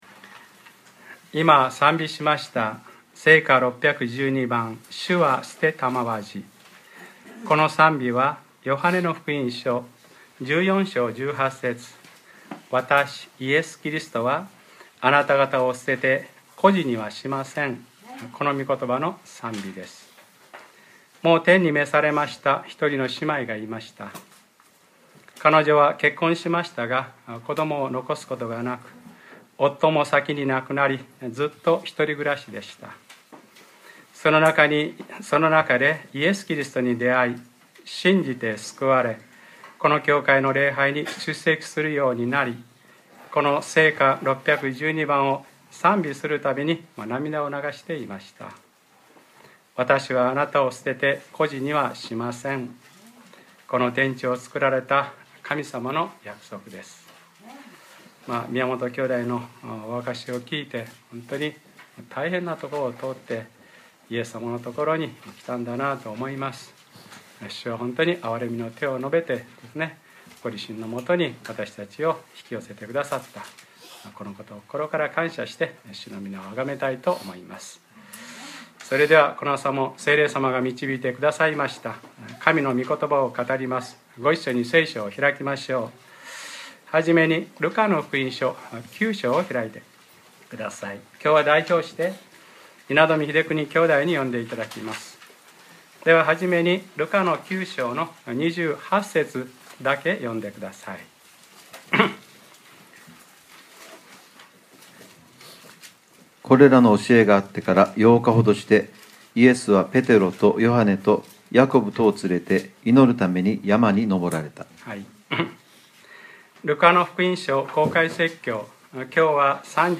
2014年 1月19日（日）礼拝説教『ルカ-３３：御子は神の栄光の輝き』